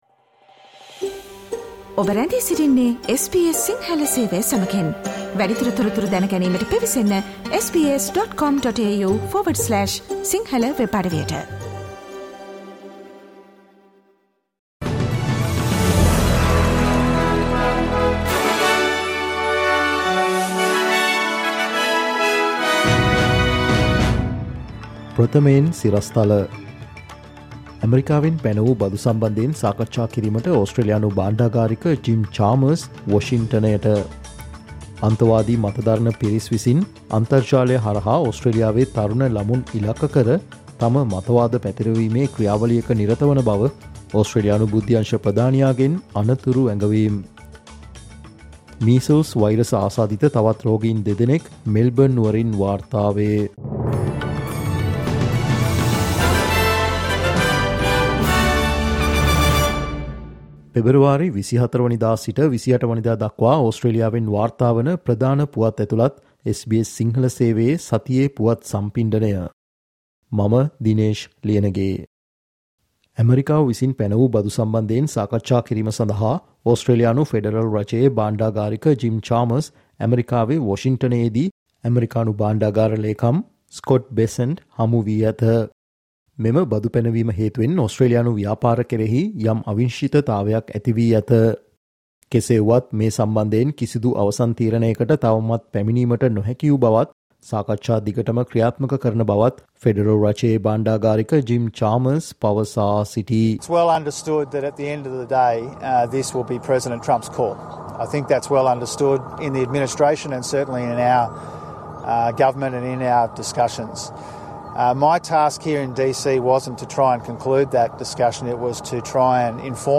Australia this week: Weekly Australian news wrap of SBS Sinhala 24 – 28 February
Listen to weekly Australian news wrap of SBS Sinhala